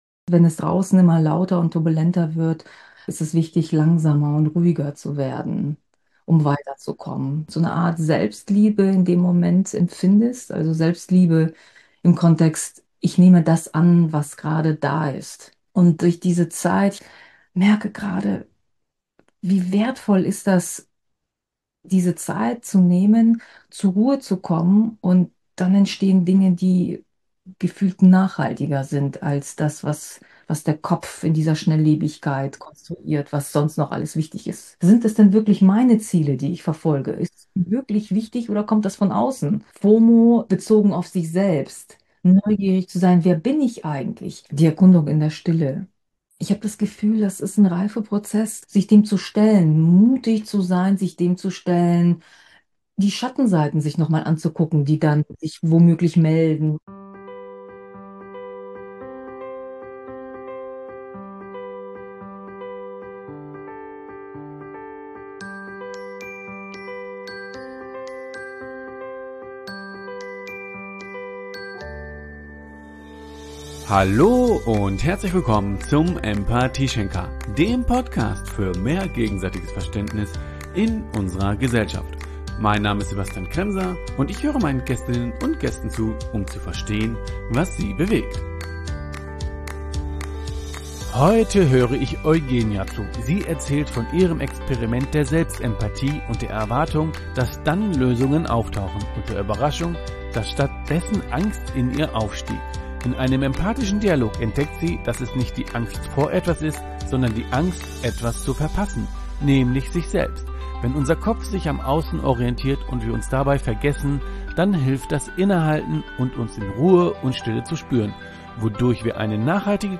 In einem empathischen Dialog entdeckt sie, dass es nicht die Angst vor Etwas ist, sondern die Angst etwas zu verpassen, nämlich sich selbst. Wenn unser Kopf sich am Außen orientiert und wir uns dabei vergessen, dann hilft das innehalten und uns in Ruhe und Stille zu spüren.